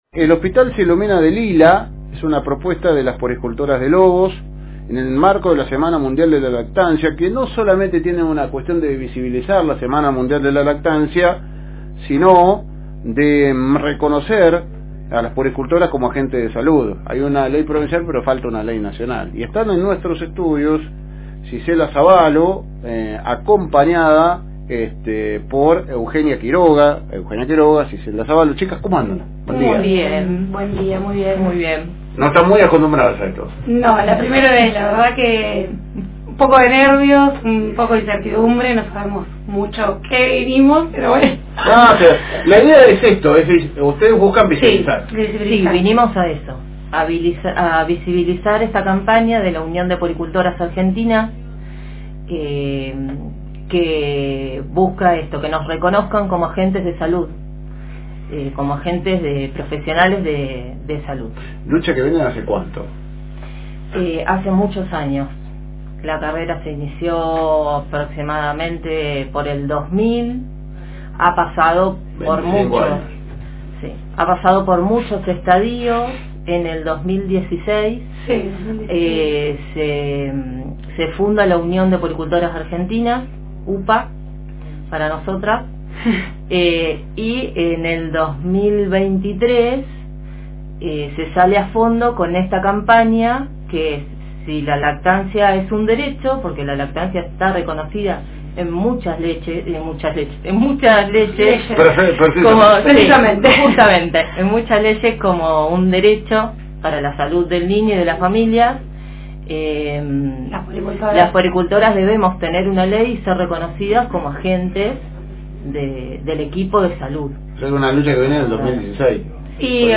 En Esto es Noticia conversamos con las puericultoras